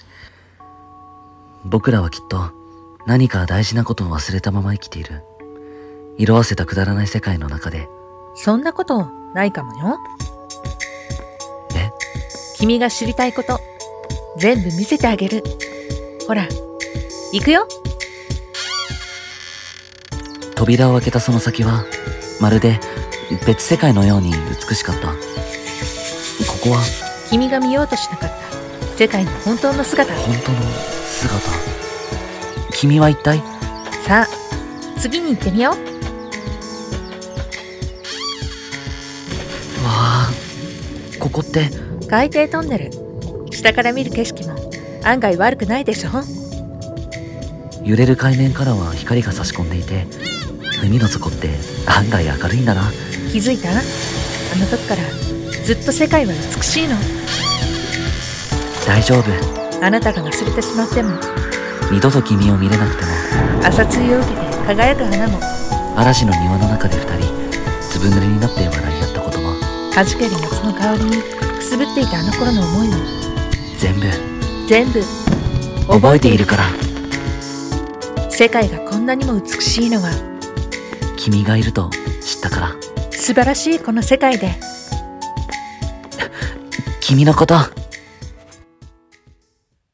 【2人声劇】とある世界で君のこと